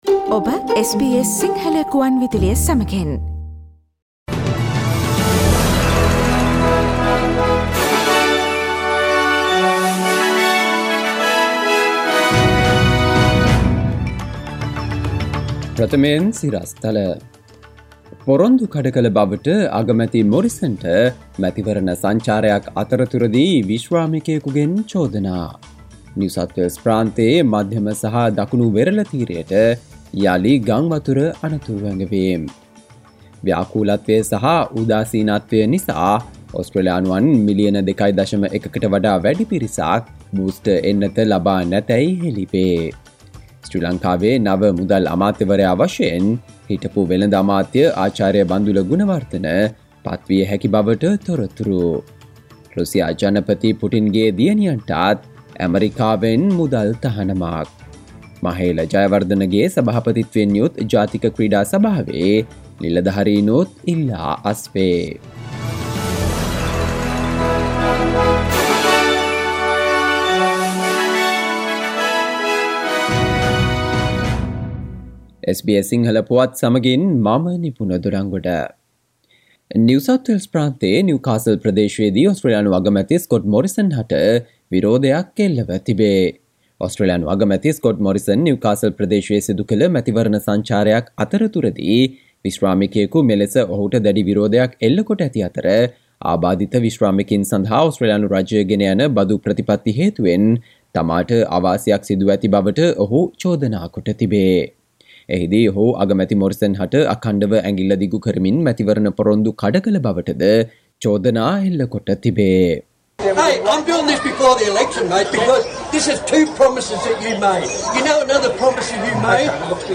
Listen to the latest news from Australia, Sri Lanka, on SBS Sinhala radio news bulletin – Thursday 07 April 2022